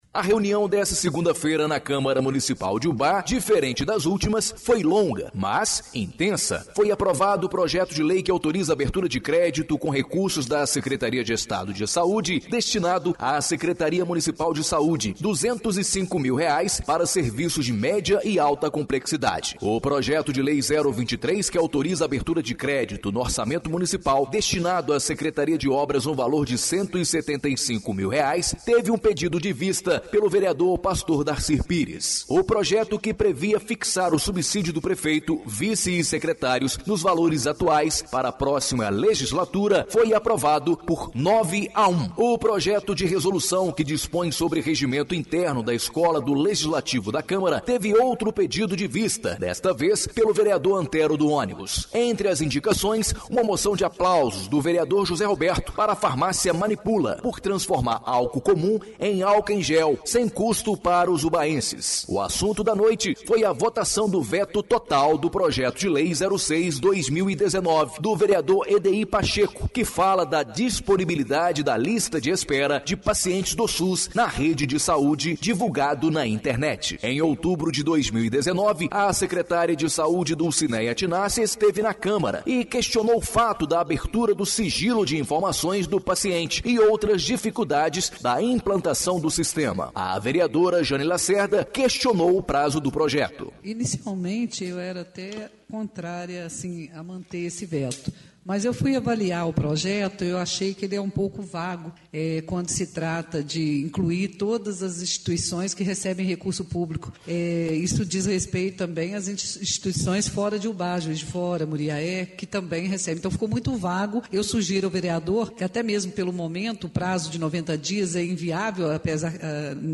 Ouça o áudio Informativo Câmara exibido na Rádio Educadora AM/FM